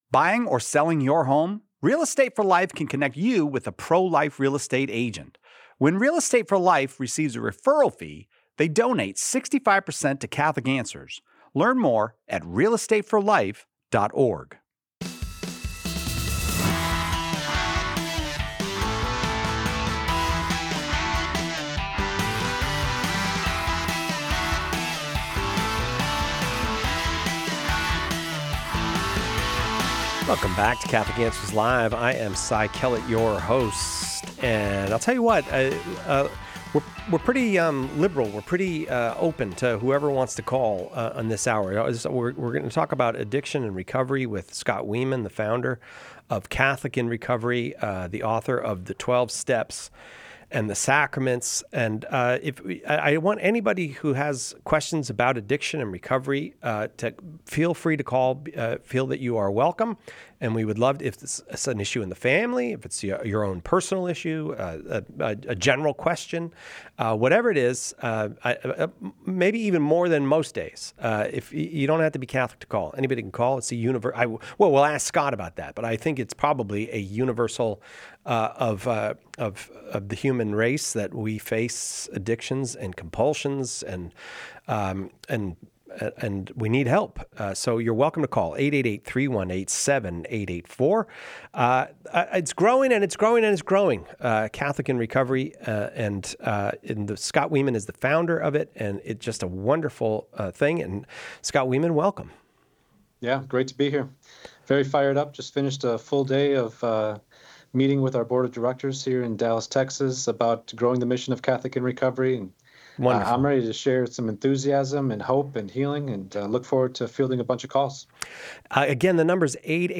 Topics include marijuana’s impact, family struggles, local recovery groups, and helping loved ones in crisis. Find hope and guidance in this powerful conversation.